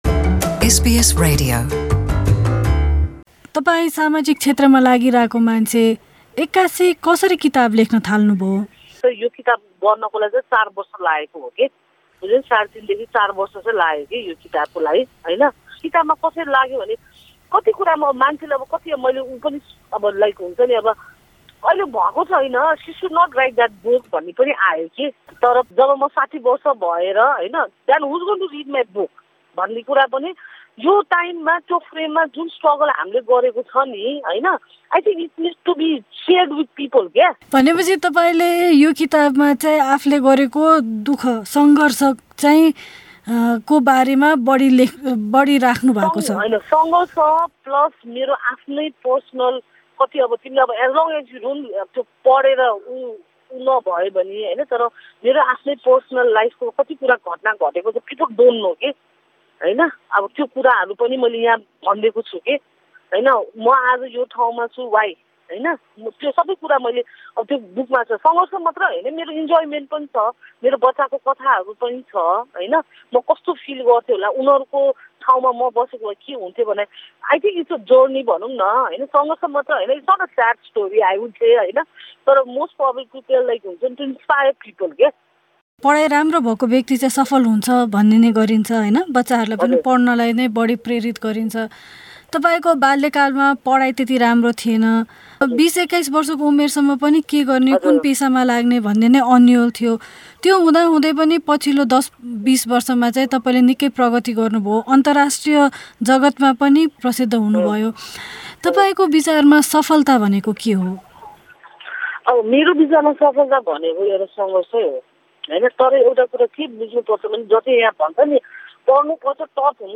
Pushpa Basnet, founder of Butterfly Project, spoke to SBS Nepali about her work to help children growing up in Nepal's prison system.